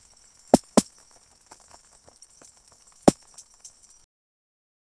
heavy-camel-hooves-drum-o-iyw4nil7.wav